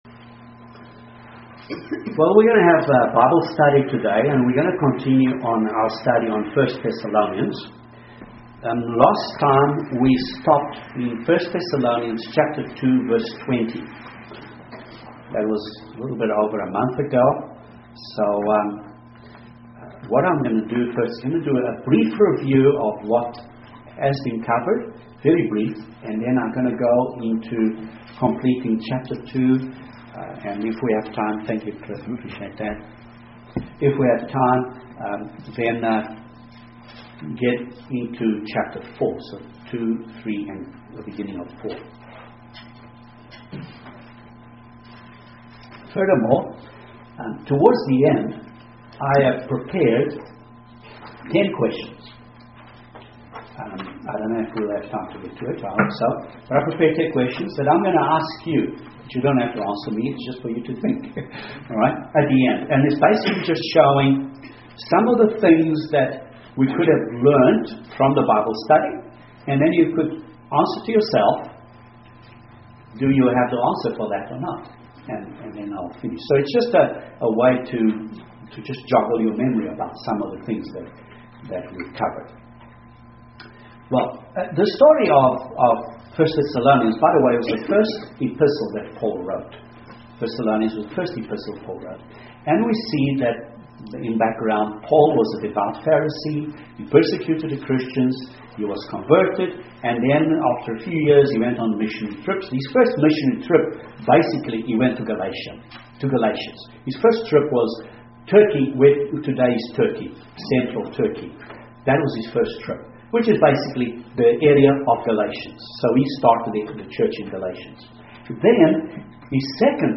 Continuation of Bible Study on 1 Thessalonians.